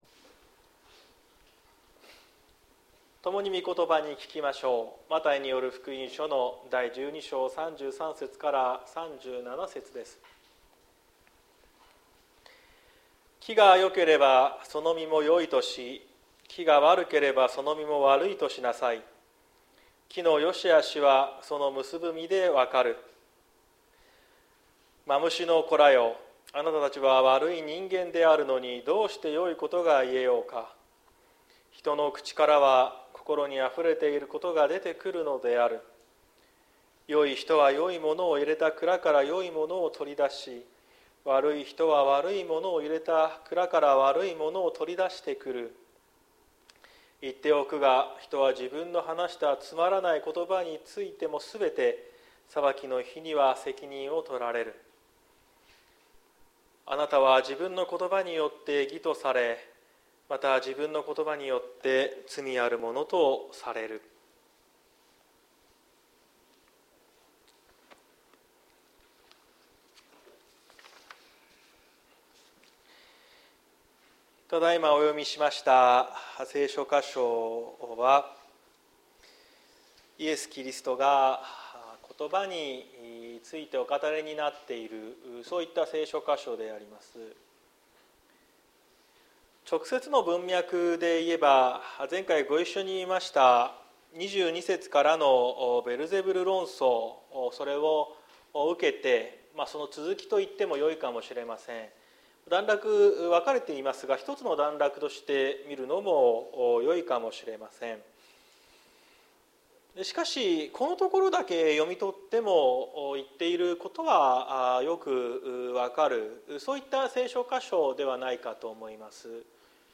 2024年04月14日朝の礼拝「良い言葉、つまらぬ言葉」綱島教会
説教アーカイブ。